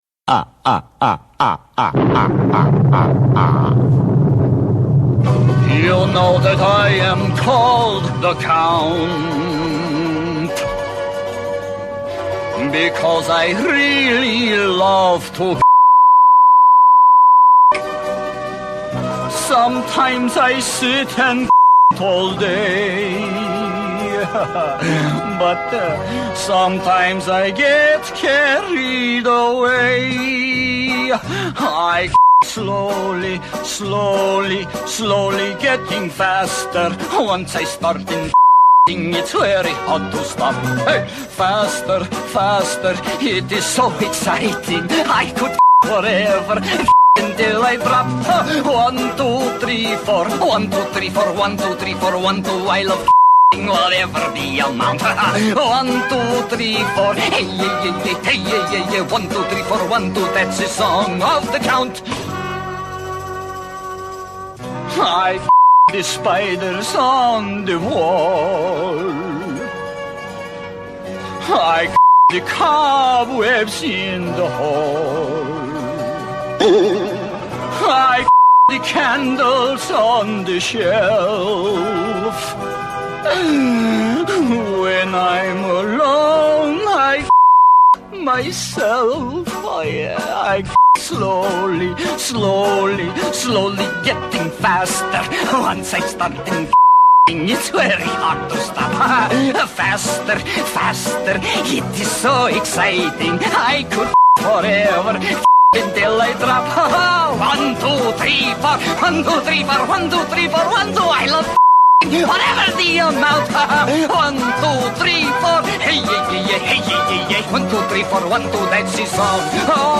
BPM184